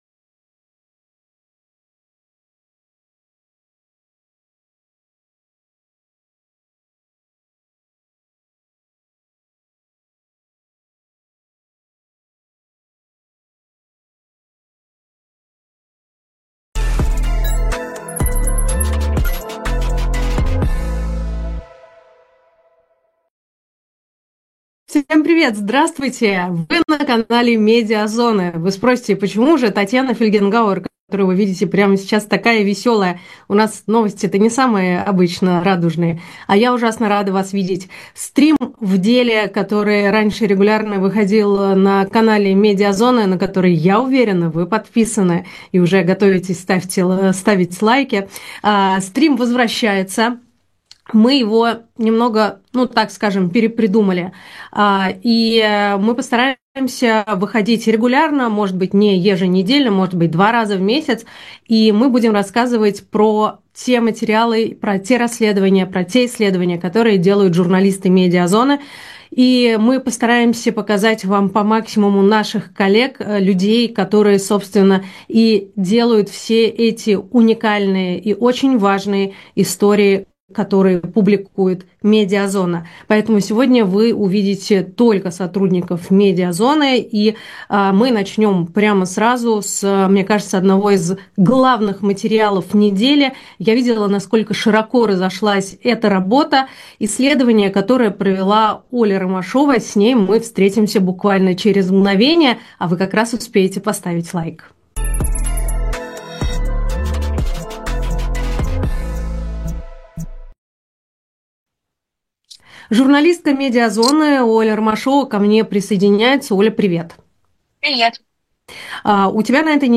Стрим ведёт Татьяна Фельгенгауэр